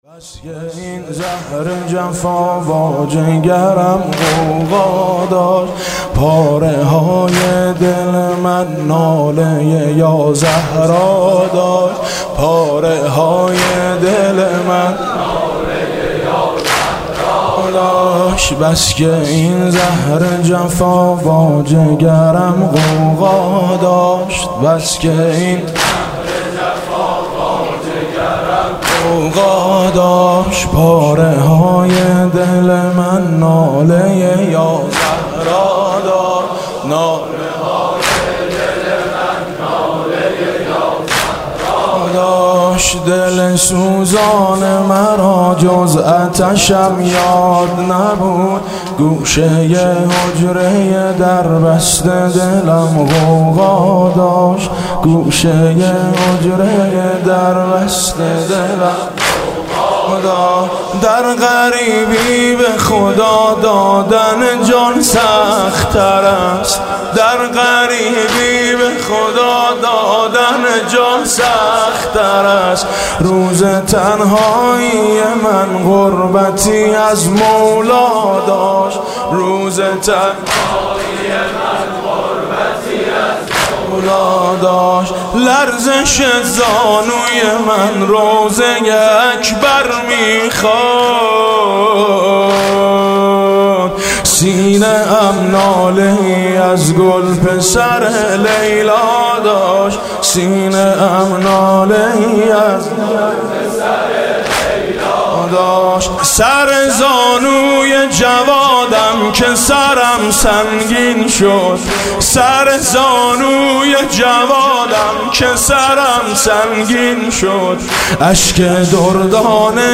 واحد: بس که این زهر جفا با جگرم غوغا داشت